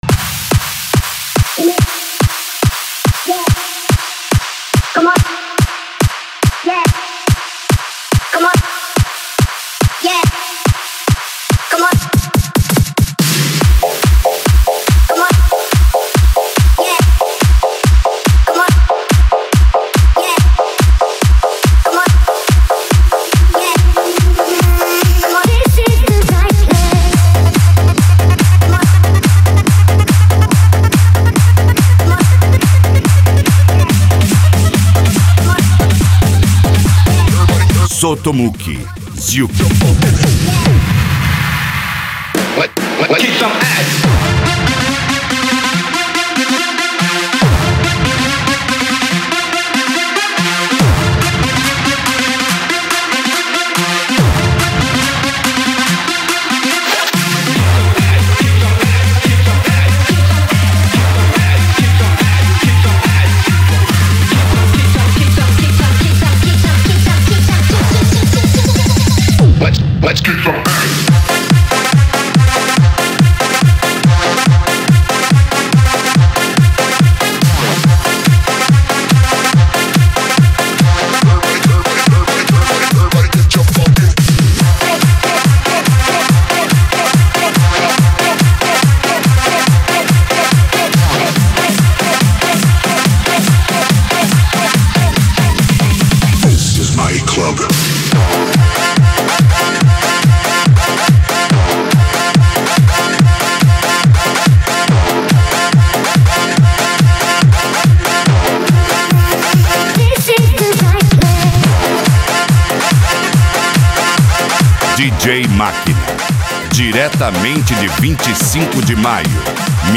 Eletronica
japan music
PANCADÃO